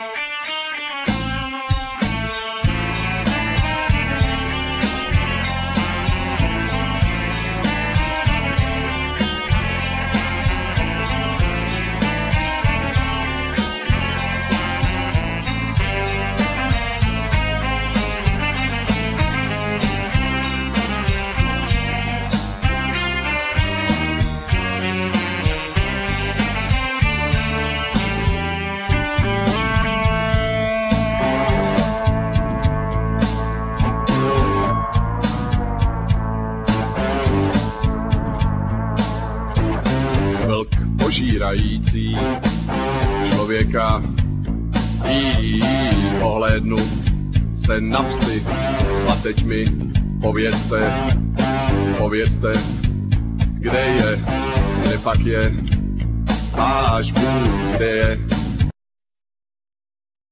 Vocal,Saxophne,Guitar solo,Synthsizer
Guitars,Synthsizer
Bass
Drums
Violin